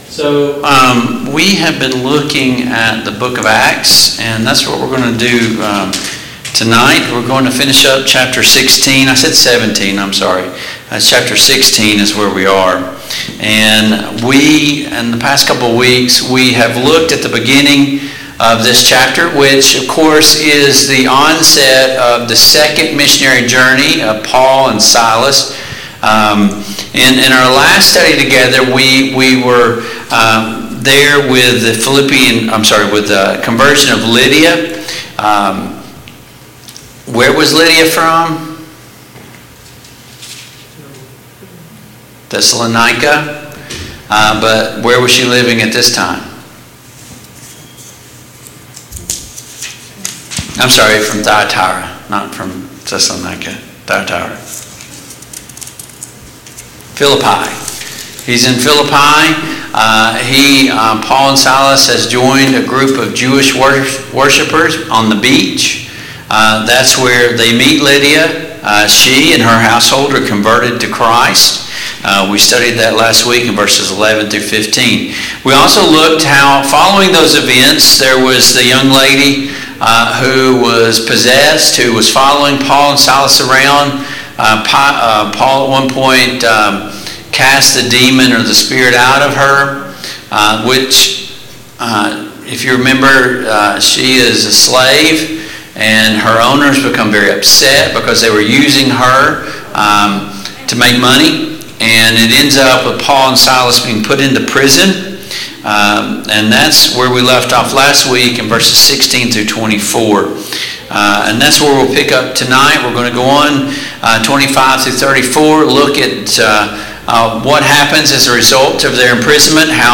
Acts 16:25-40 Service Type: Mid-Week Bible Study Download Files Notes « He died so that we might live! 9.